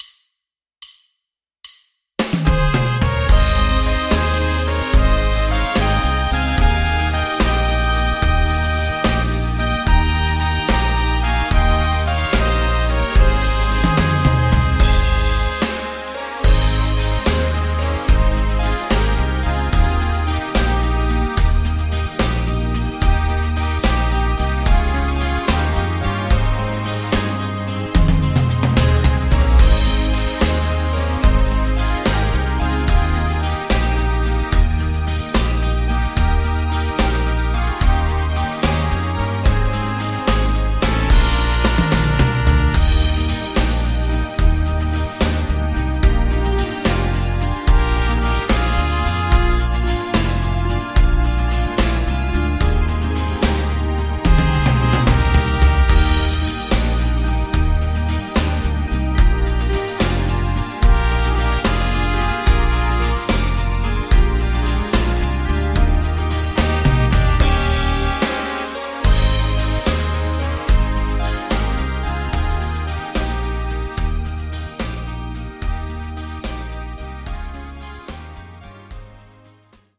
Midifile | Playback, Karaoke, Instrumental